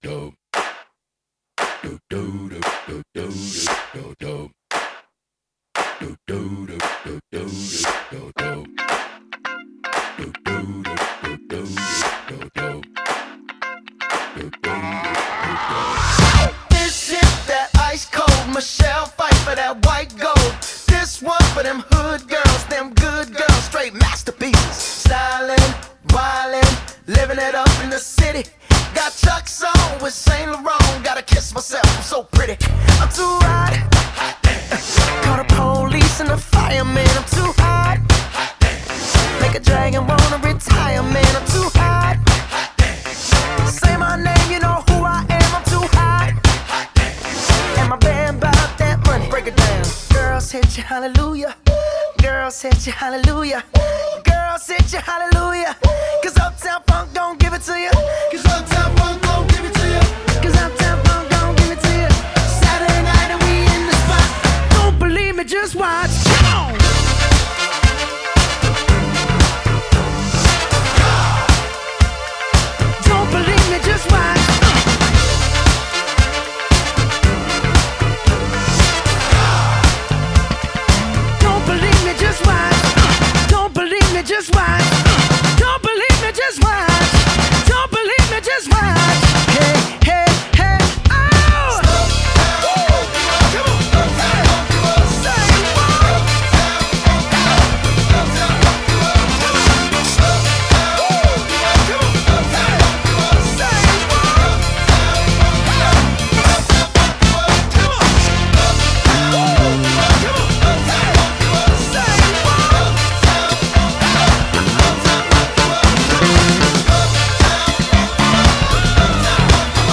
BPM115
MP3 QualityLine Out